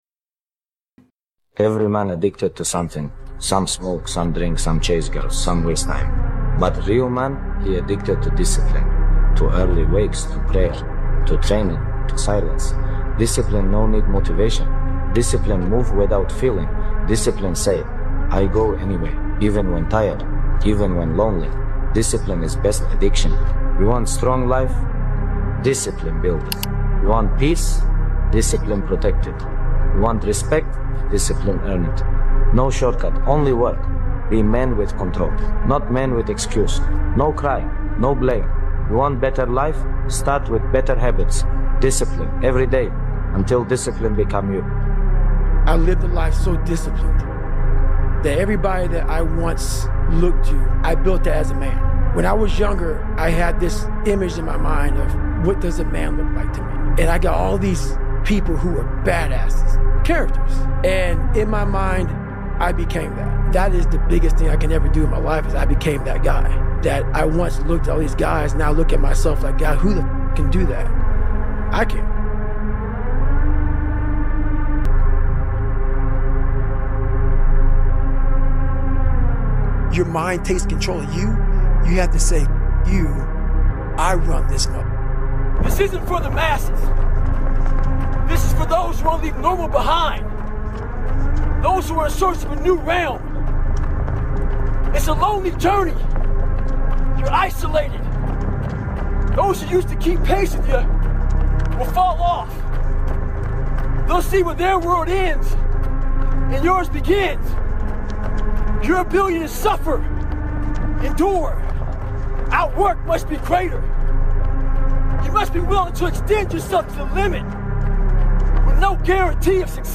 Even I'm Tired, Even Not Motivated | Most Powerful Motivation Speech of the Planet 4:11